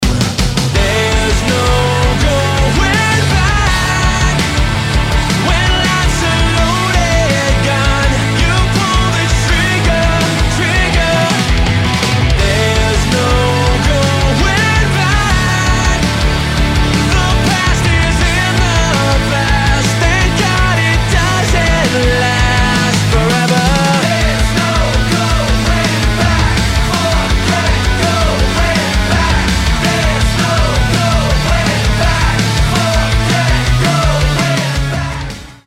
• Качество: 320, Stereo
мужской вокал
мелодичные
Metal
vocal